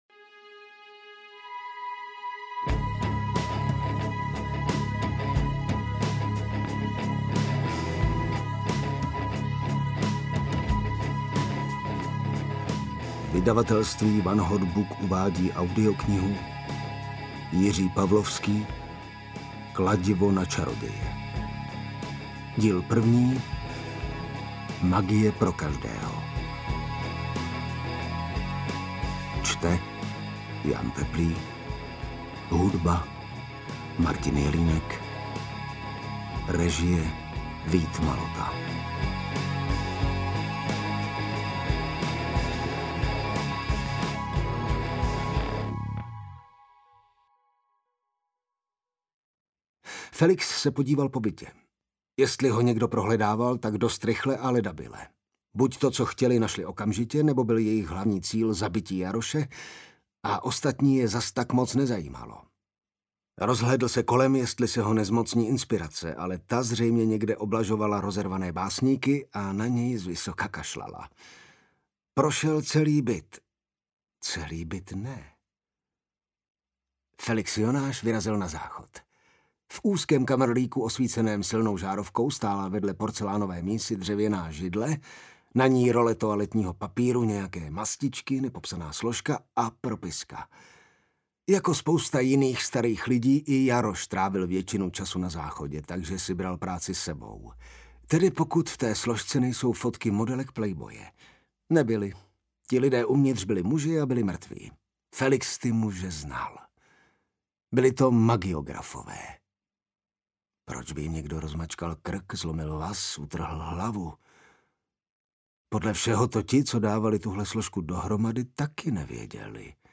Ukázka z knihy
kladivo-na-carodeje-magie-pro-kazdeho-audiokniha